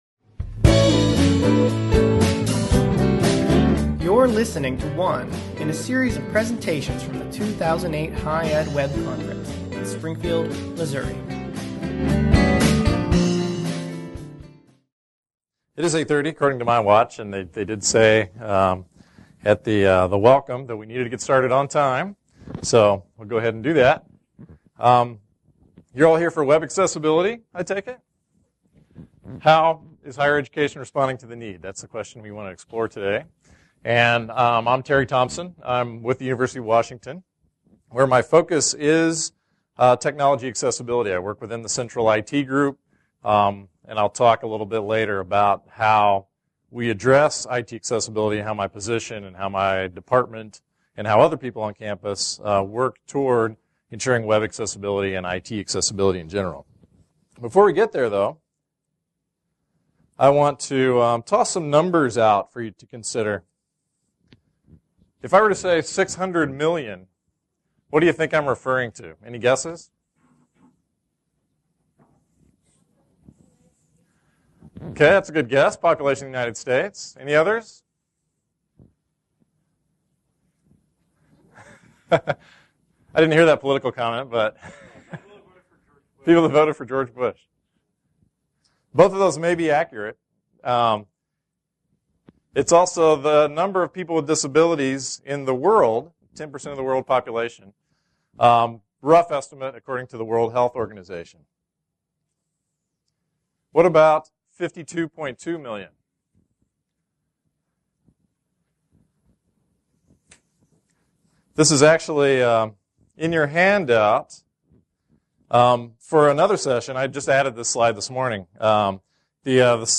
Plaster Student Union Traywick Parliamentary Room